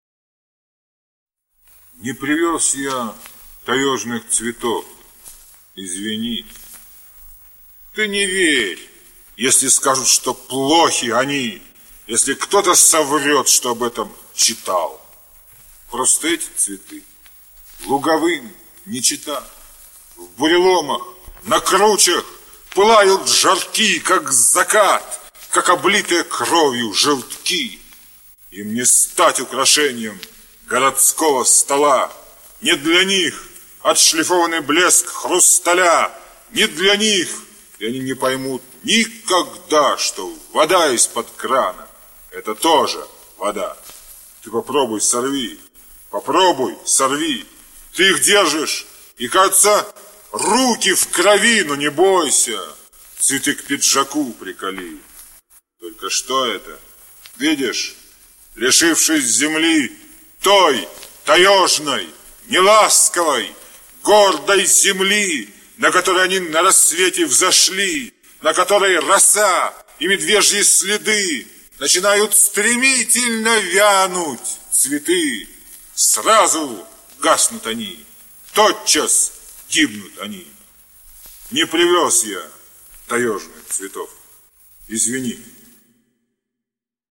1. «Роберт Рождественский – Таежные цветы (читает автор)» /